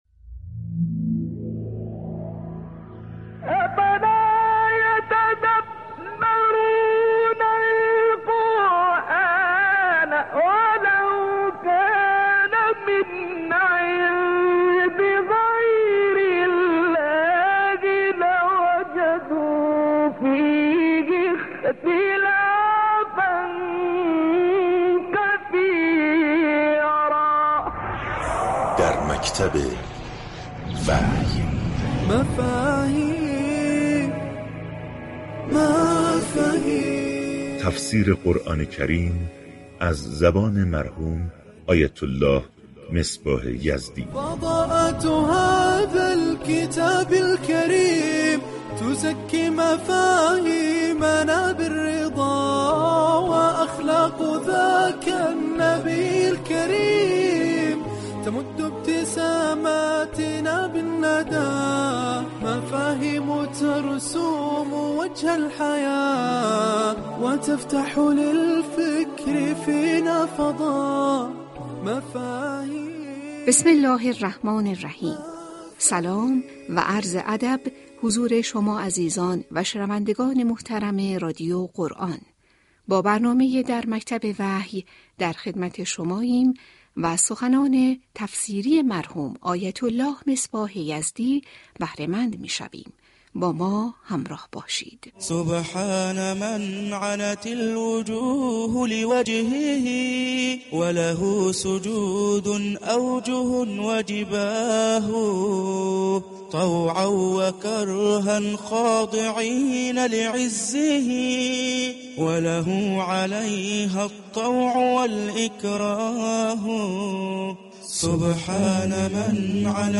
برنامه در مكتب وحی با محوریت تفسیر آیات و روایات قرآن كریم با سخنرانی علمای دینی از رادیو قرآن پخش می شود.